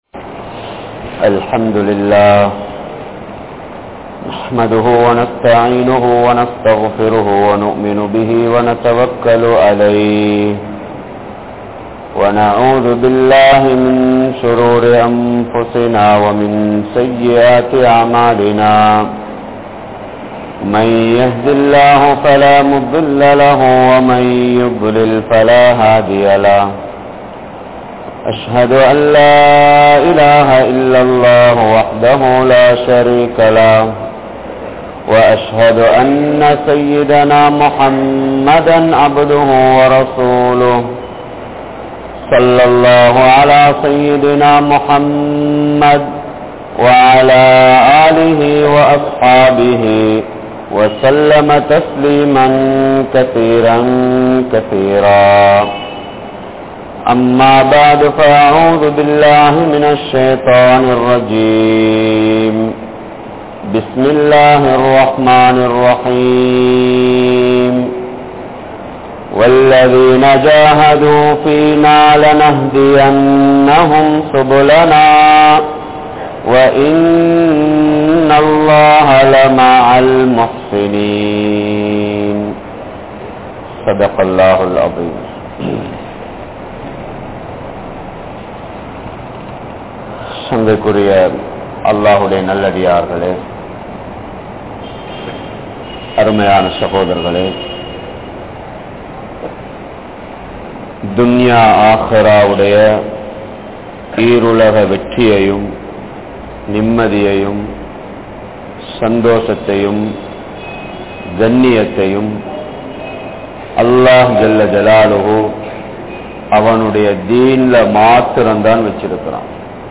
Neengal Muslima? Mumina? (நீங்கள் முஸ்லிமா? முஃமினா?) | Audio Bayans | All Ceylon Muslim Youth Community | Addalaichenai